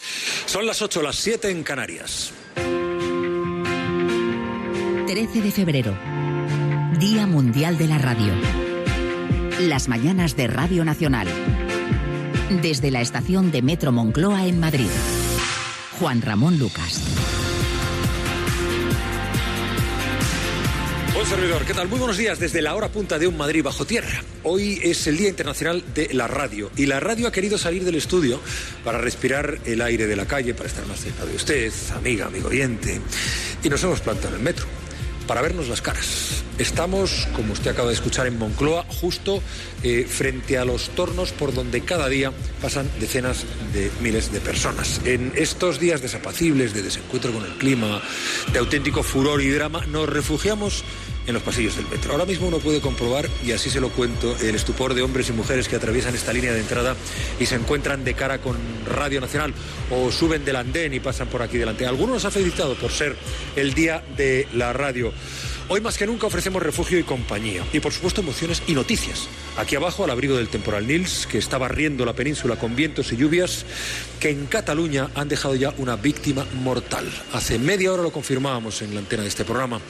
Fragment de l'inici del programa des de la estació de metro de Moncloa a Madrid, amb motiu del Dia Mundial de la Ràdio.
Info-entreteniment